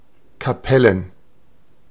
Ääntäminen
Ääntäminen Tuntematon aksentti: IPA: /kaˈpɛlən/ Haettu sana löytyi näillä lähdekielillä: saksa Käännöksiä ei löytynyt valitulle kohdekielelle. Kapellen on sanan Kapelle monikko.